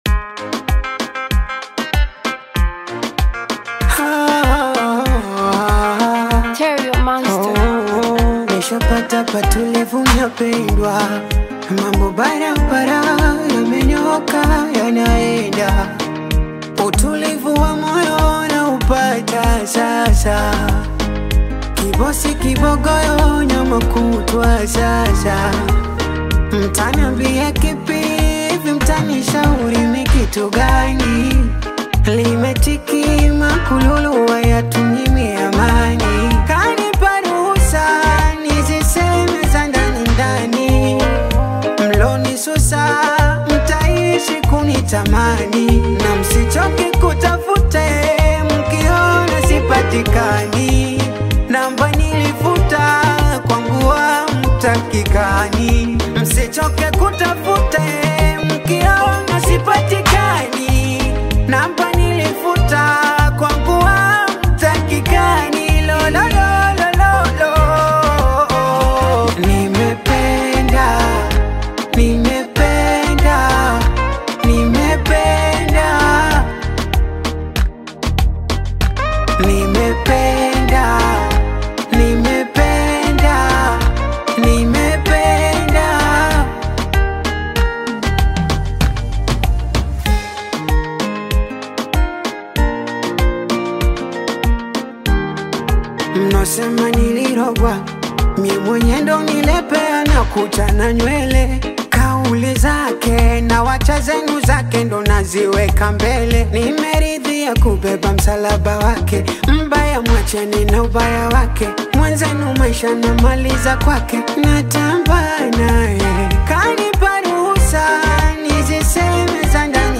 rap single